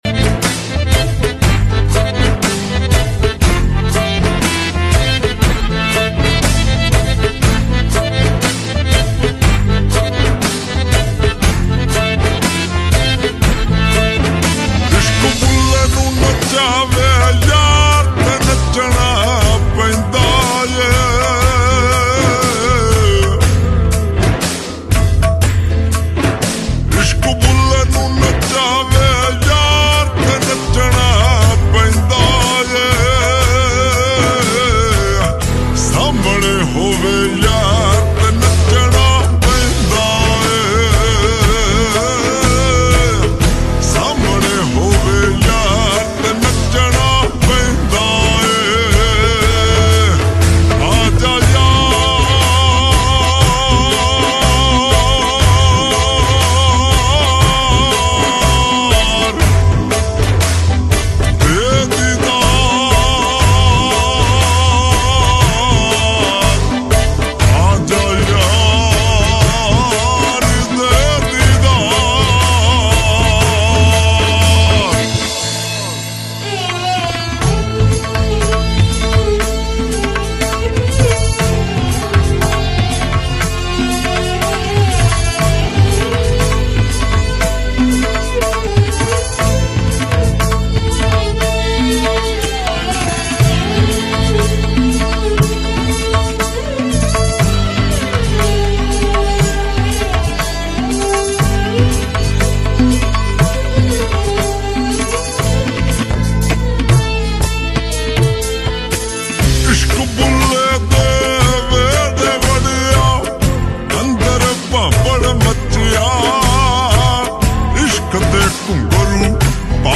Sad Song Slowed+Reverb